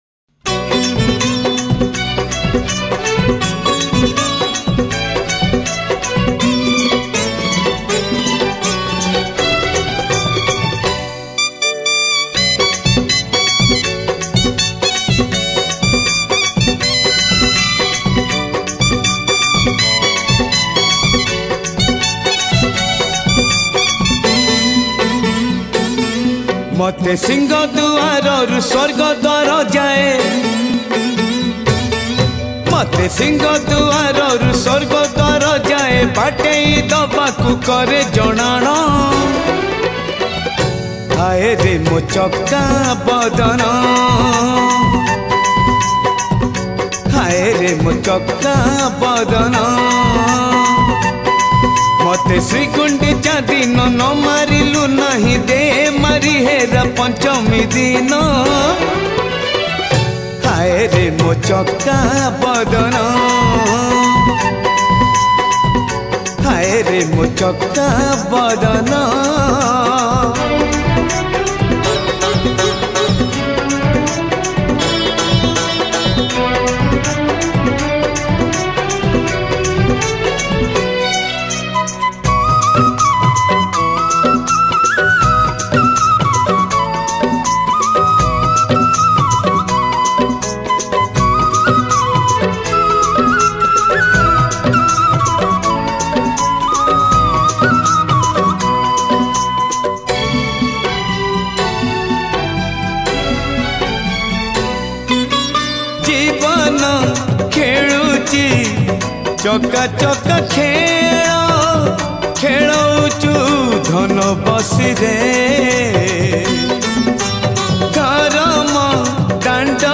Ratha Yatra Odia Bhajan 2000-21 Songs Download